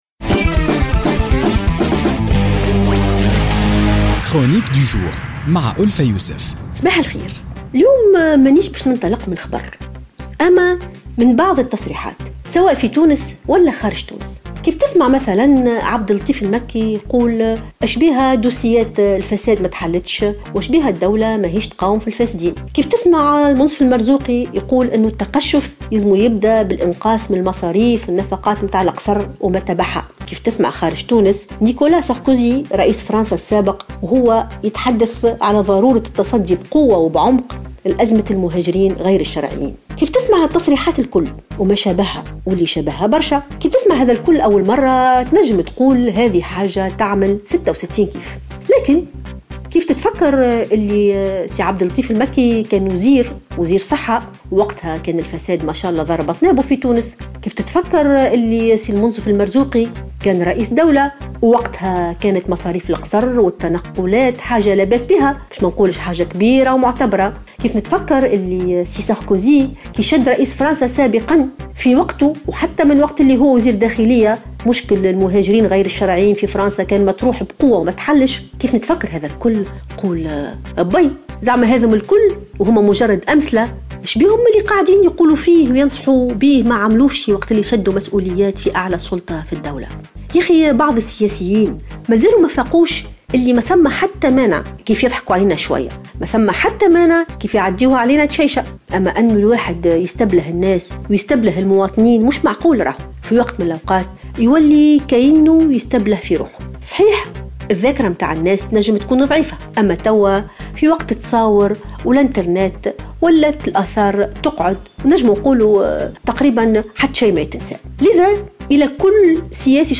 انطلقت الكاتبة ألفة يوسف في افتتاحية اليوم الثلاثاء 4 أكتوبر 2016 من تصريحات بعض المسؤولين السابقين في تونس والخارج والتي لم يتمكنوا من تجسيمها عندما كانوا في موقع السلطة .